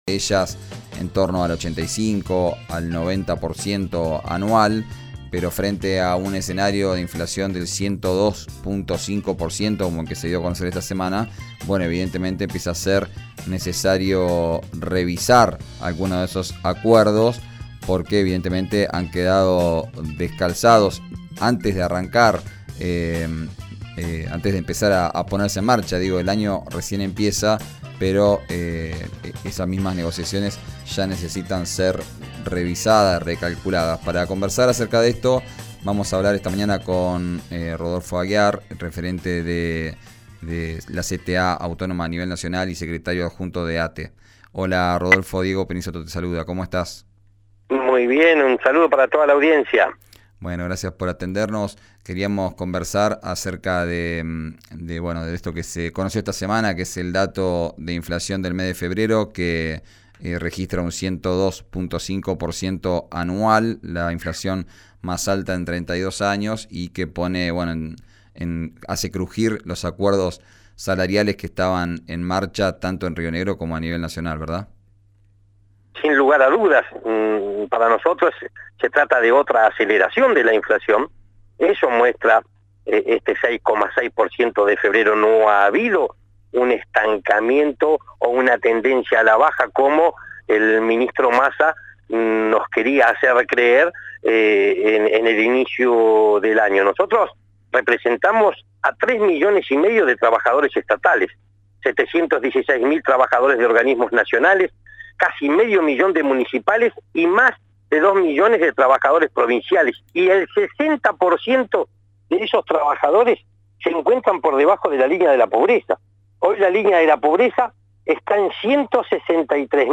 en diálogo con «Arranquemos» por RÍO NEGRO Radio.